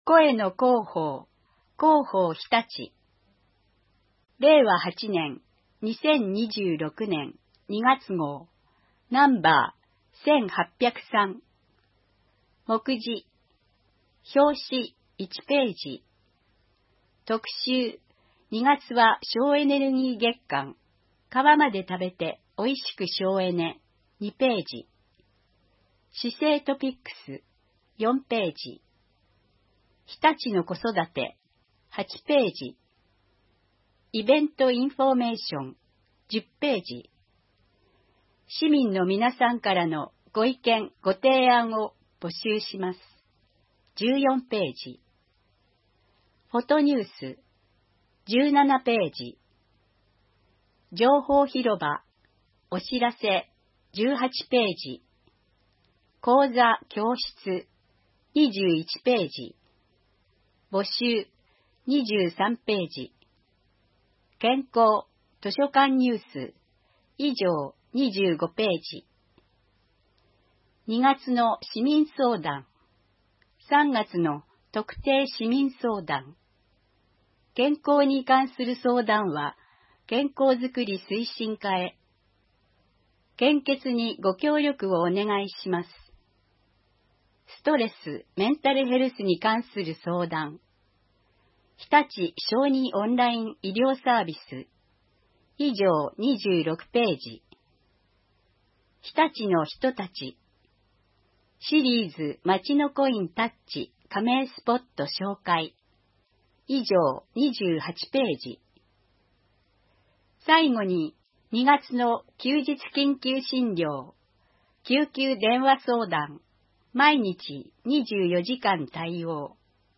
声の市報を読みあげます。